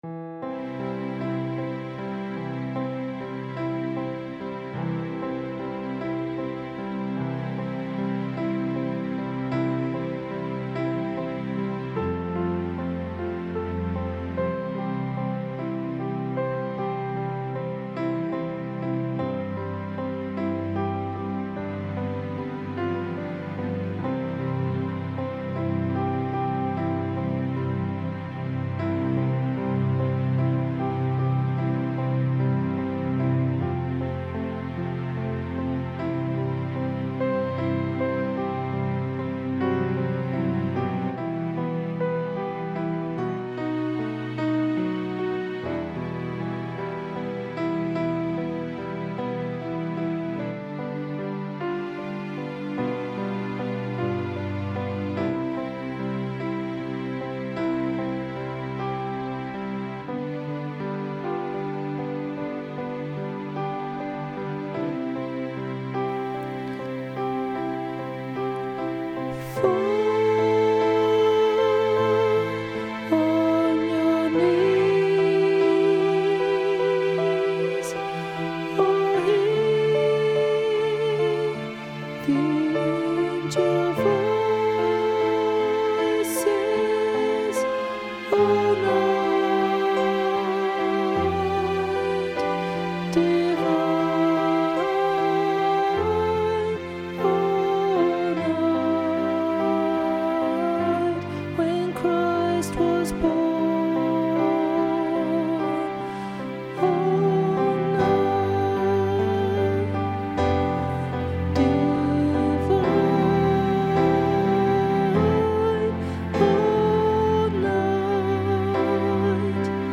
O holy night Alto
O-holy-night-alto.mp3